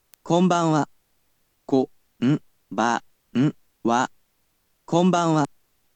You’ll be able to hear an organic voice in another resource, but for now, he’ll definitely help you learn whilst at least hearing the words and learning to pronounce them.